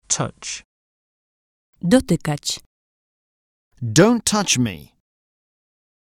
Angielski Fiszki mp3 1000 najważniejszych słów i zdań - audiobook